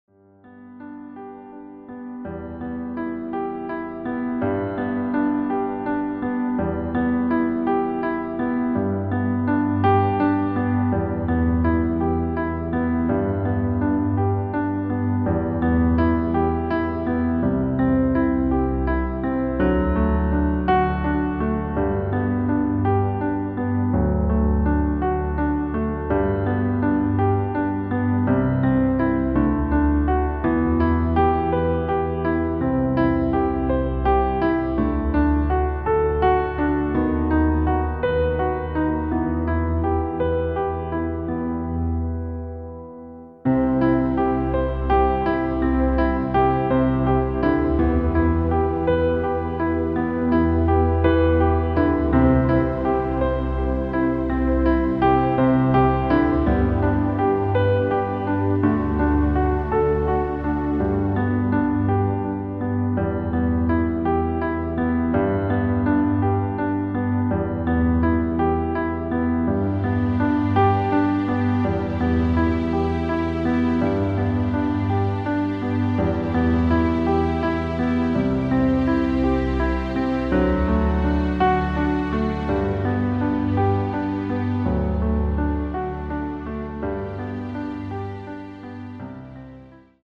• Tonart: Eb Dur, E-Dur, F-Dur, G-Dur
• Art: Klavierversion mit Streicher
• Das Instrumental beinhaltet keine Leadstimme
Lediglich die Demos sind mit einem Fade-In/Out versehen.
Klavier / Streicher